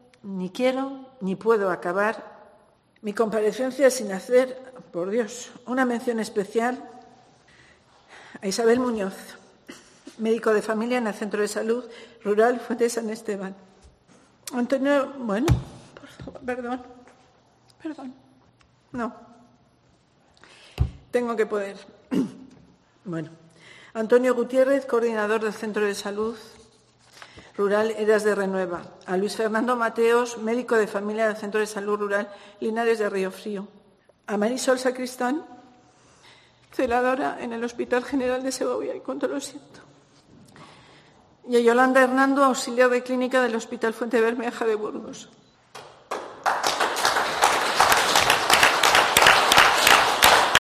La consejera de Sanidad de Castilla y León rompe a llorar al leer los nombres de los médicos fallecidos